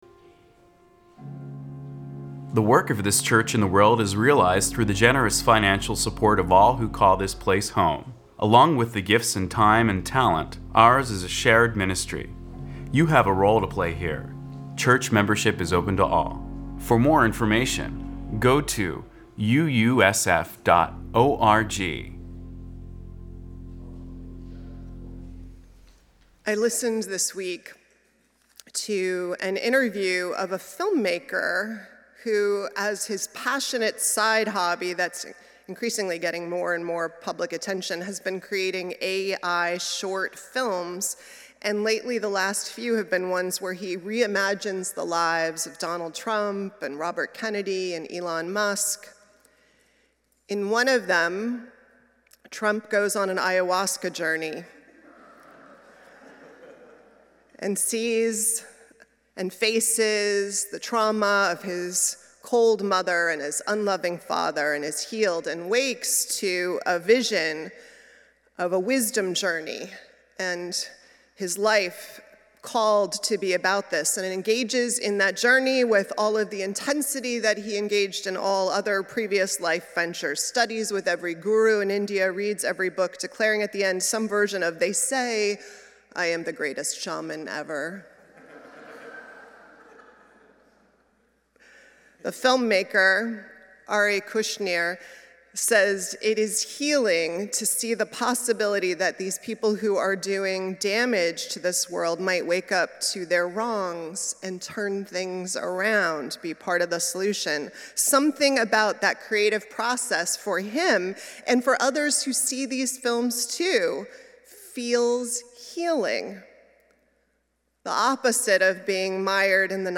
1 Hearts Ready For The Seed(From our 5-18-25 Worship) 25:03